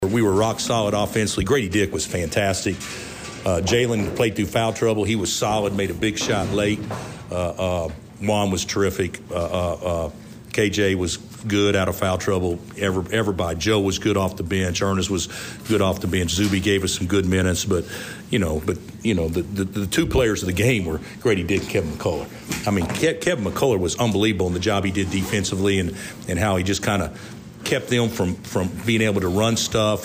Kansas Coach Bill Self called the 2nd half special.
2-15-bill-self.mp3